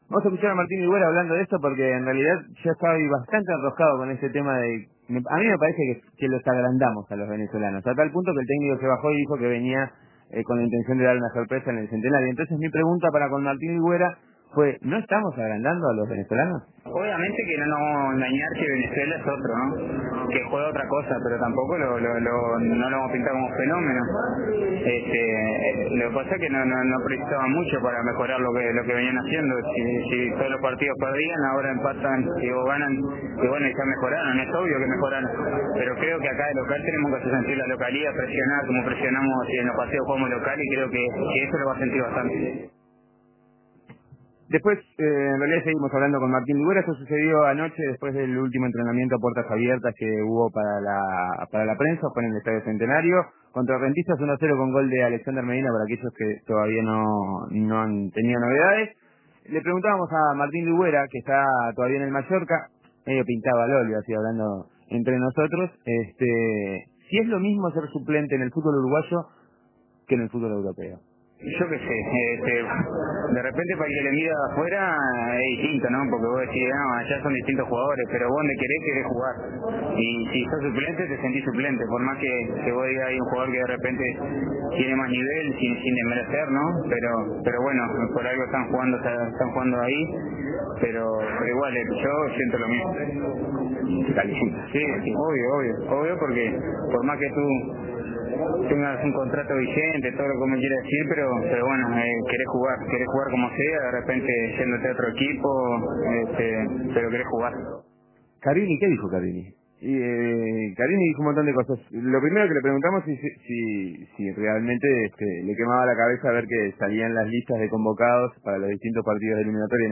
En el Aeropuerto, Martín Ligüera, Fabián Carini, Álvaro Recoba y Walter Pandiani hablan del partido ante Venezuela.